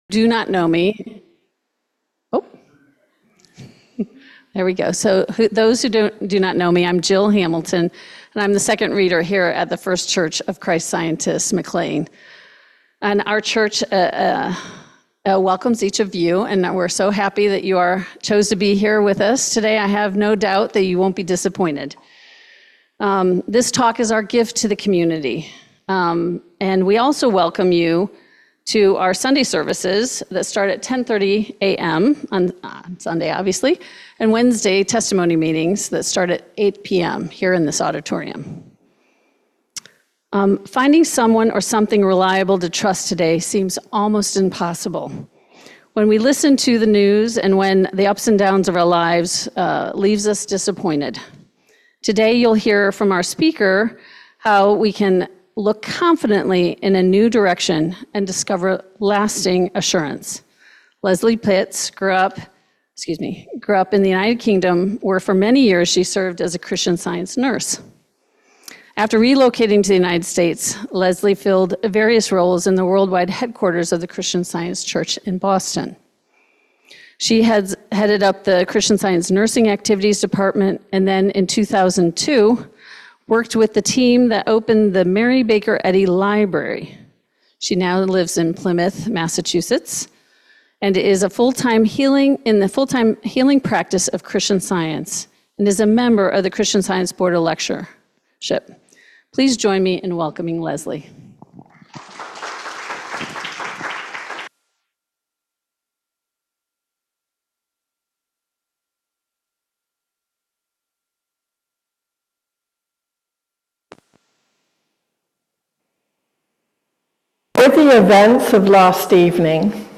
Listen to the replay audio from a recent lecture titled